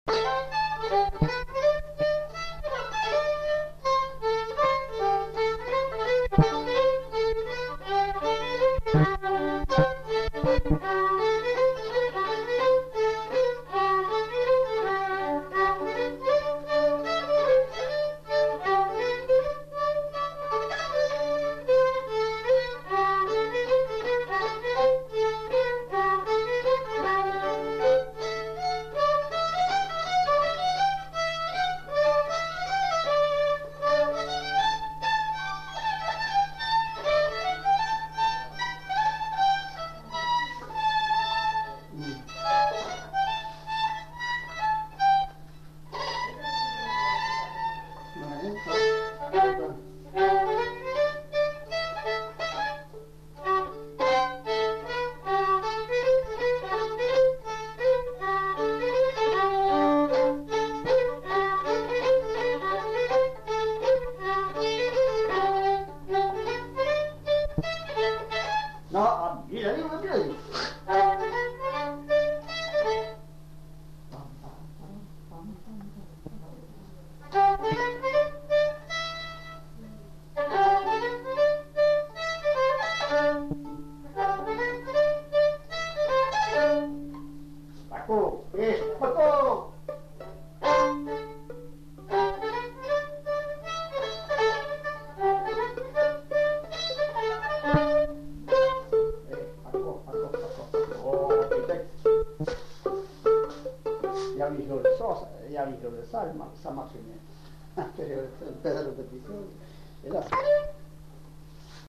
Lieu : Saint-Michel-de-Castelnau
Genre : morceau instrumental
Instrument de musique : violon
Danse : scottish double
Notes consultables : 2 violons.